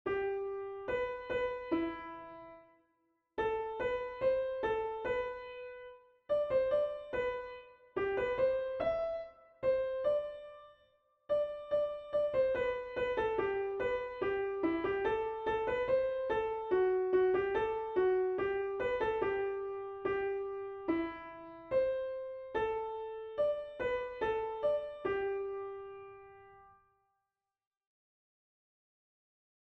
Unknown Genre: Secular, Canon
Language: English Instruments: A cappella
First published: 2025 Description: A four voice round with optional coda.